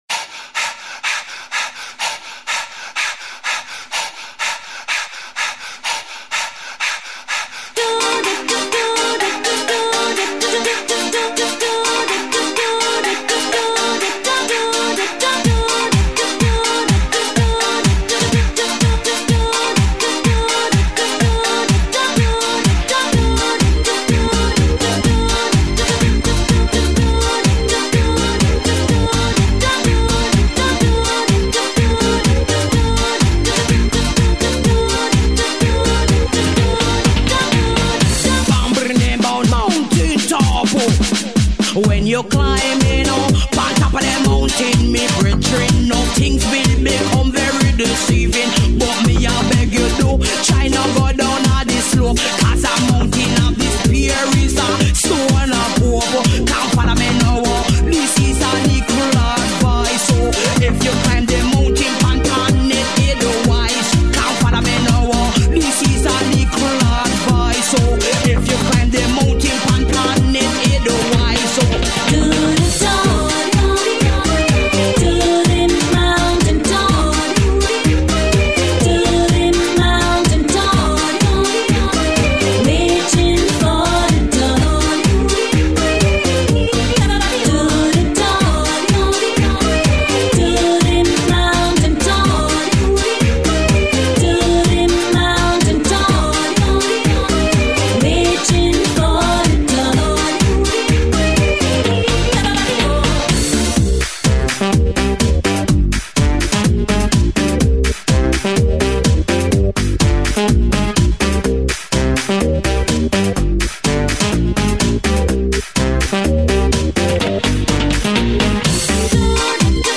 это вдохновляющая песня в жанре поп с элементами фолка